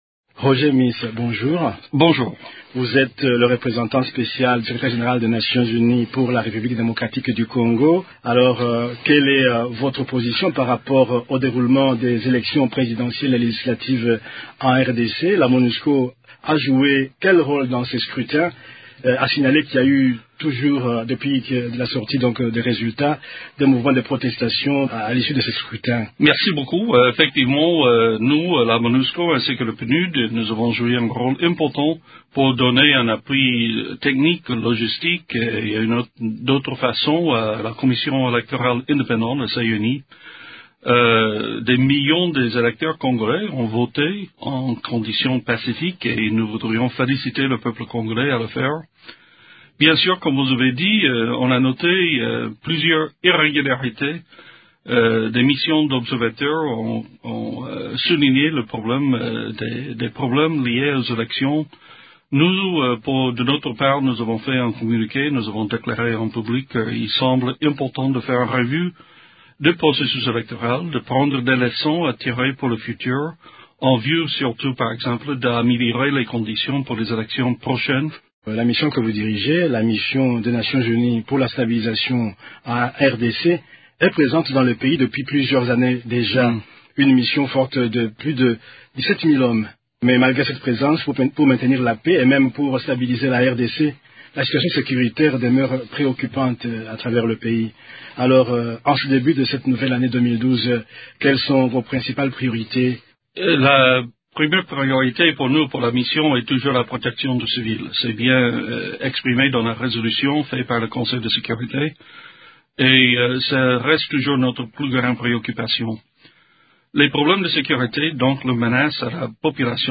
La veille de ce rendez-vous, Roger Meece a accordé une interview à la radio des Nations Unies sur plusieurs sujets d’actualité impliquant la Mission de l’ONU pour la stabilisation en RDC (Monusco) qu’il dirige.